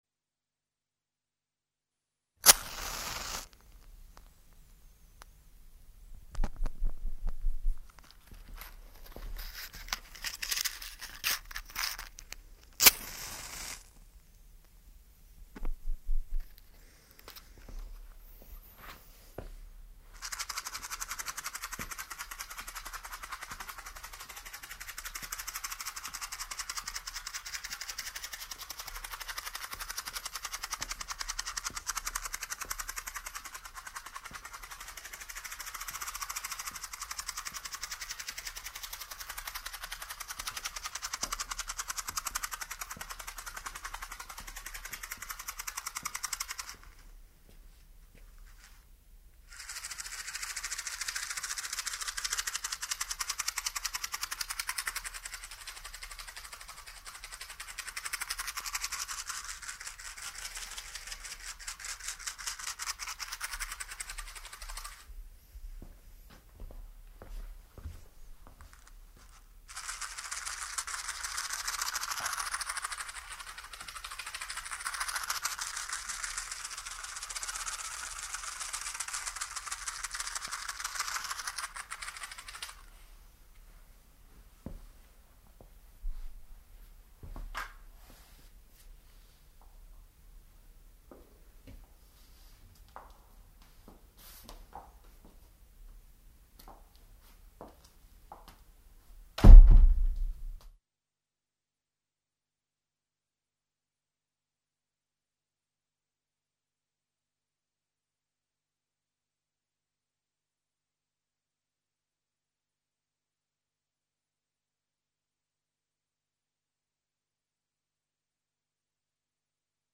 ネットで見つけたホロフォニクスで録音されたと思われる音源。
マッチ箱を擦る音・マッチ箱を揺する音